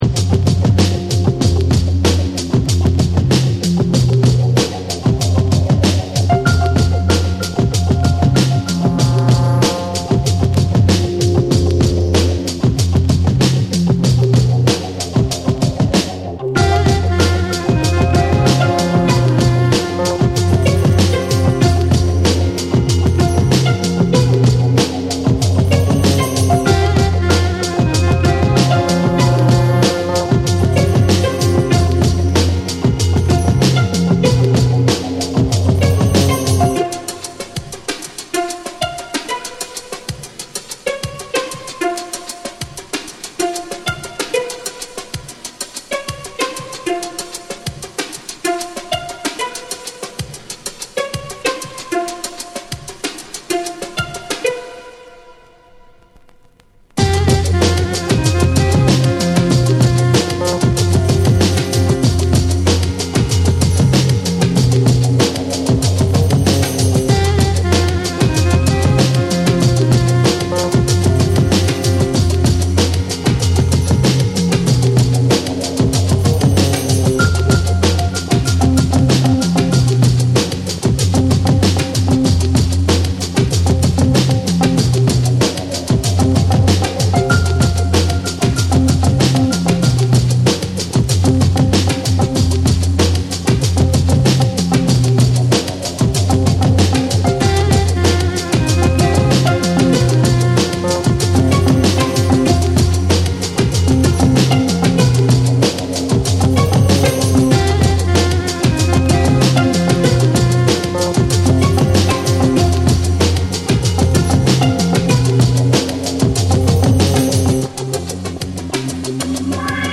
BREAKBEATS / JUNGLE & DRUM'N BASS